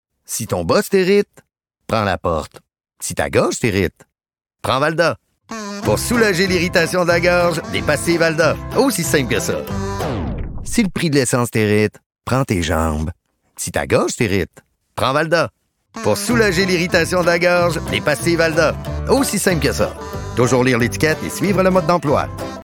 SPOT RADIO VALDA – 1